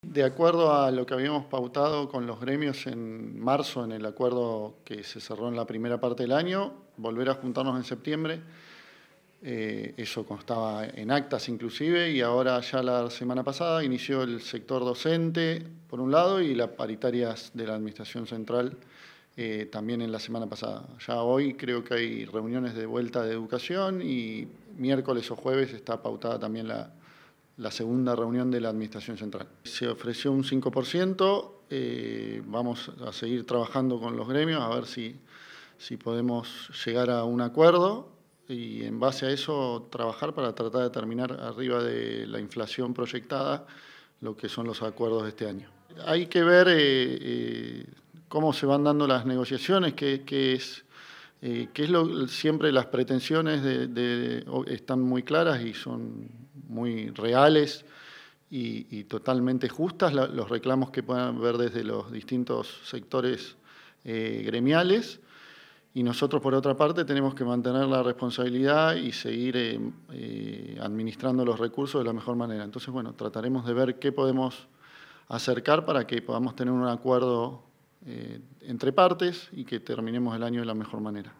Así lo manifestó, el ministro de Economía, Finanzas e Infraestructura, Ignacio Perincioli en diálogo con LU 85 TV Canal 9.